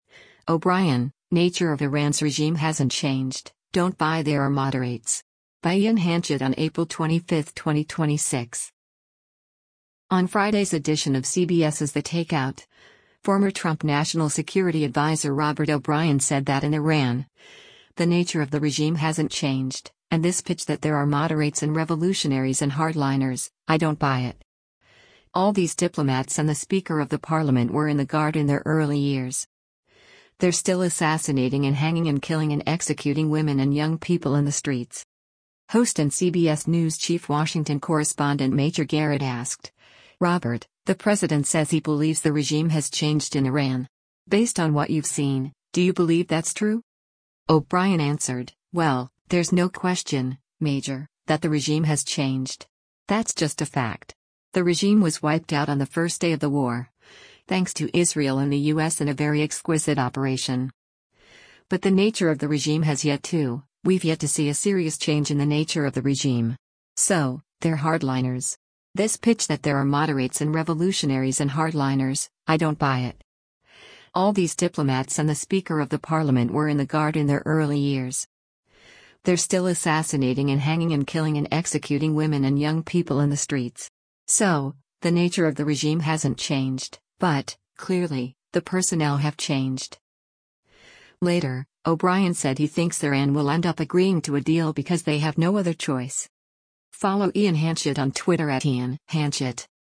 Host and CBS News Chief Washington Correspondent Major Garrett asked, “Robert, the president says he believes the regime has changed in Iran. Based on what you’ve seen, do you believe that’s true?”